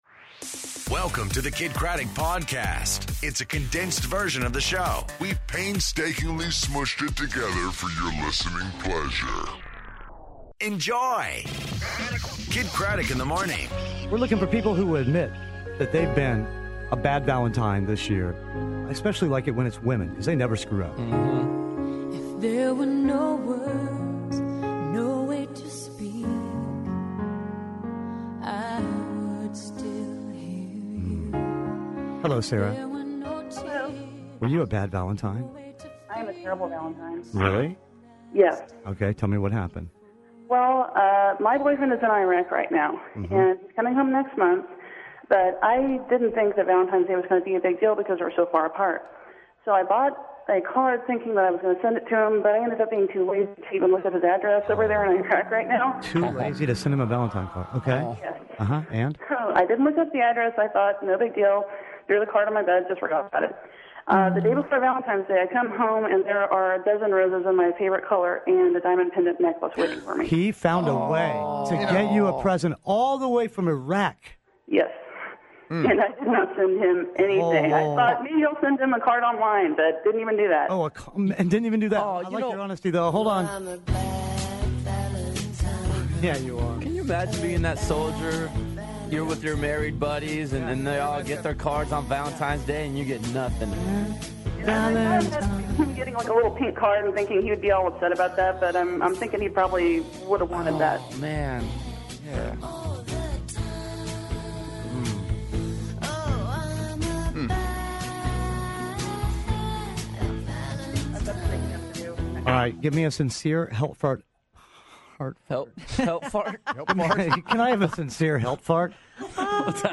Bad Valentine's Day, The Magic Man, and Brandi Glanville on the phone